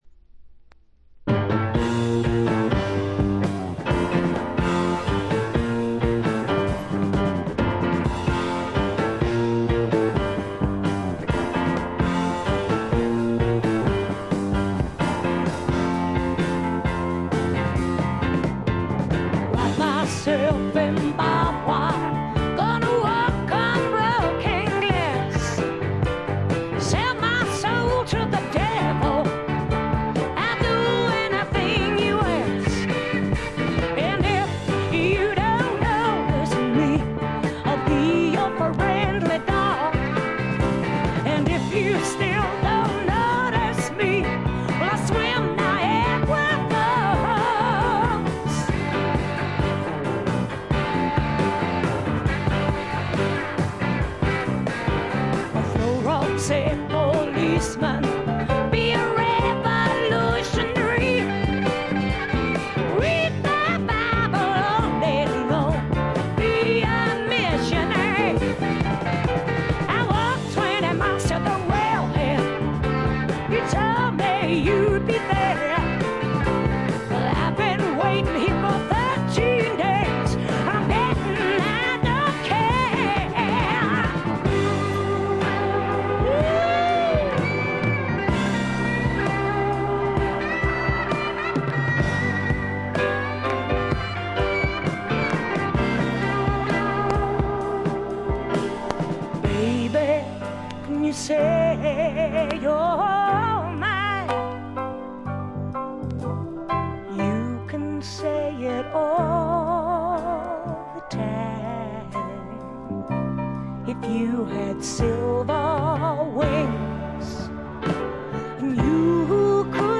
重厚なスワンプロックの大傑作です。
試聴曲は現品からの取り込み音源です。
※長尺のため途中まで。1:30あたりからプツ音7回。